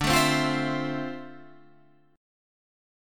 Dm9 chord